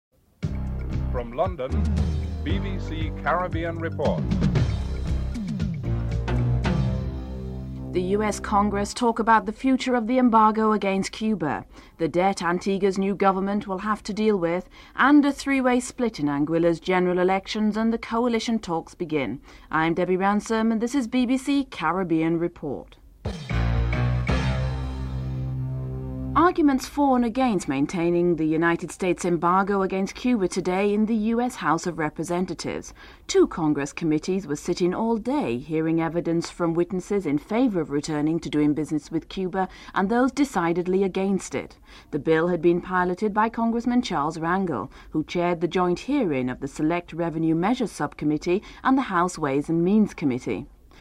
1. Headlines
7. Theme music (14:42-15:02)